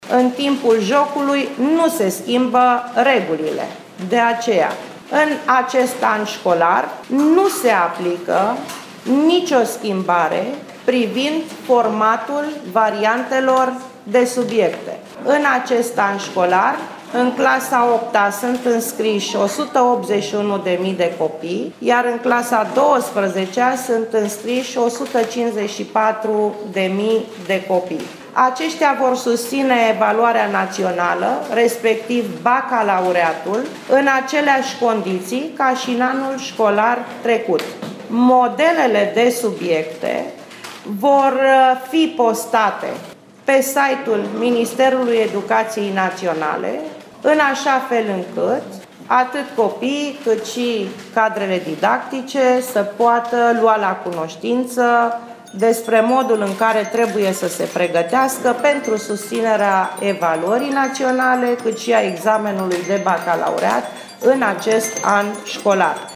Ministerul Educaţiei a renunţat la schimbarea tipului de examen pentru evaluarea naţională şi bacalaureat, cel puţin pentru acest an şcolar. Ministrul interimar, Rovana Plumb, a anunţat decizia după consultările avute, ieri, cu reprezentanţii cadrelor didactice, părinţilor şi elevilor: